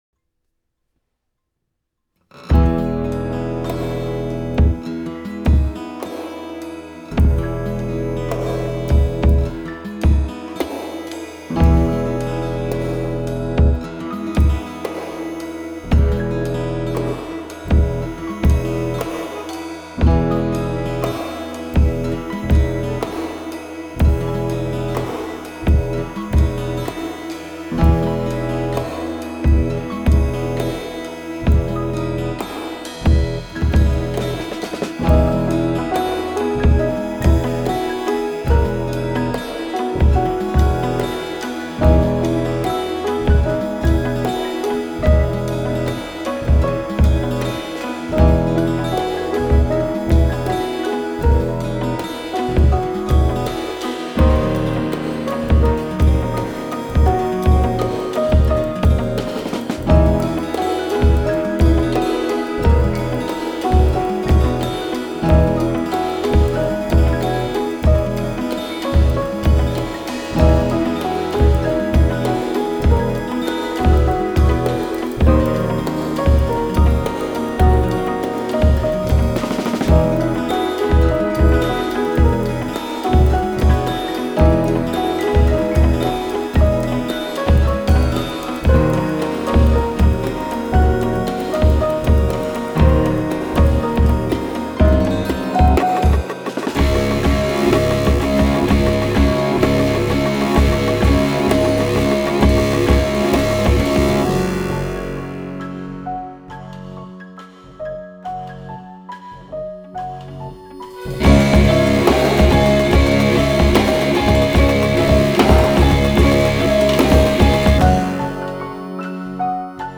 I recorded piano, and drums, guitar, and bass.
After I had a bunch of layers, I started mixing them together.
here’s the full song in this unfinished nu metal state.)